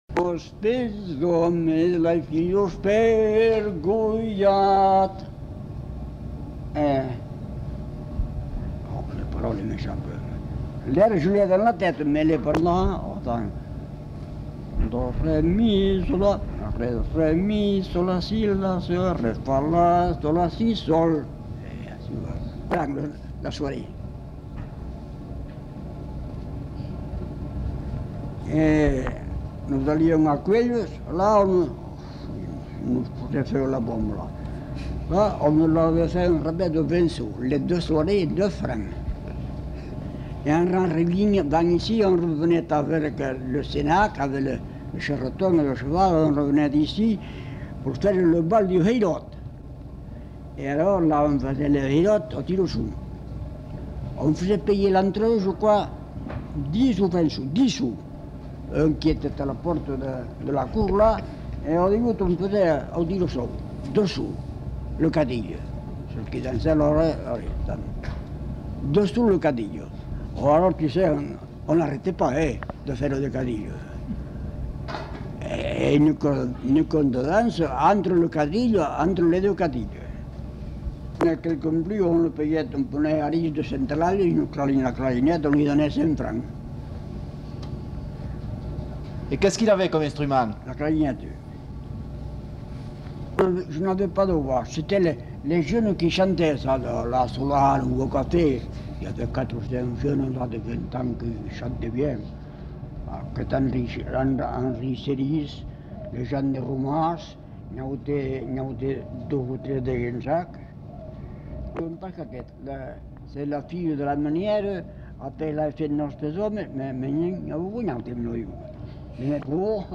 Polka piquée (notes chantées)